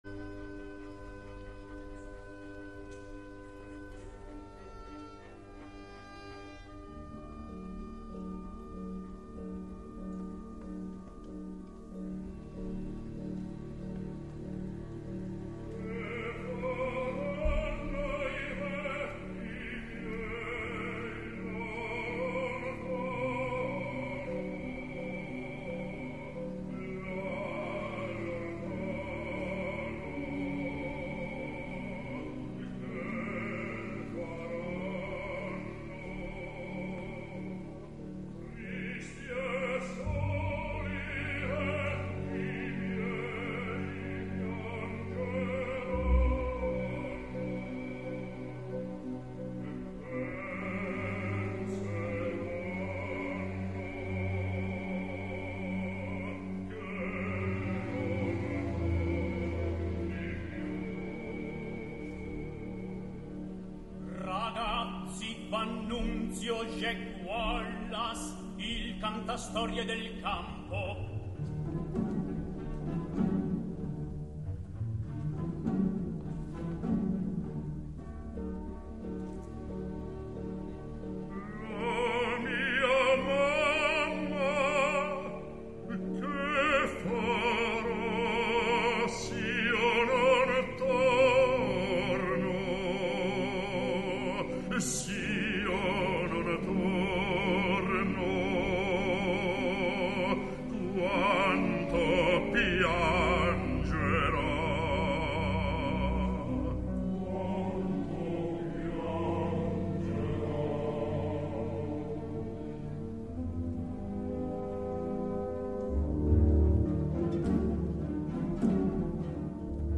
Jake Wallace [Bariton]